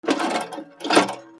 体重計
/ M｜他分類 / L10 ｜電化製品・機械